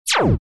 Звуки бластера
8-битный звук бластера